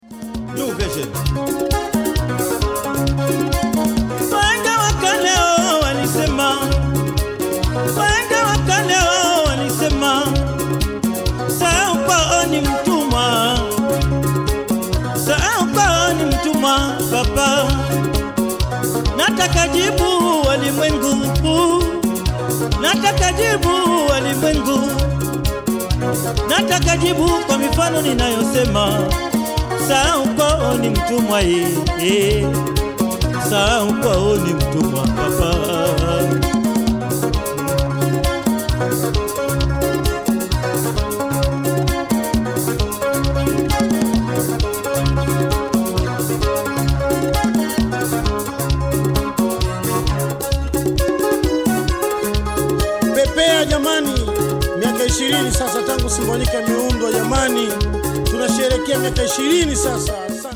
recorded this album in Holland in 1991
extra guitar and percussion being added later